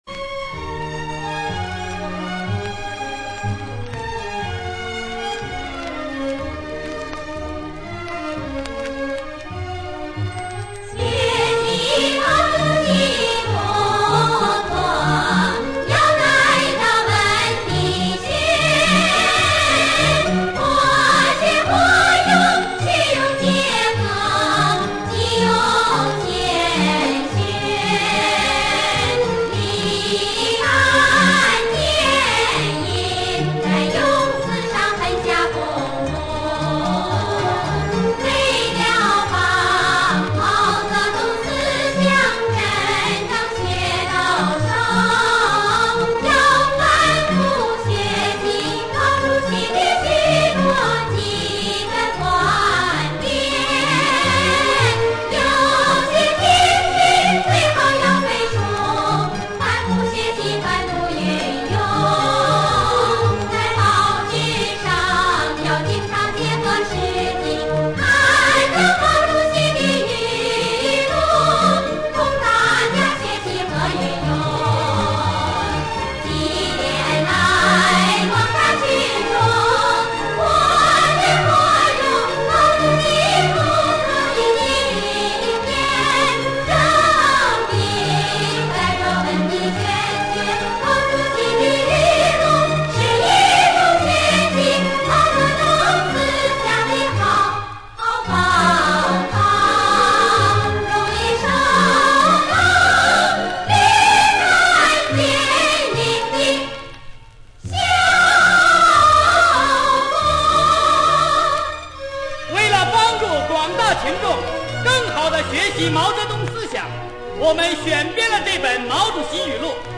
（大联唱）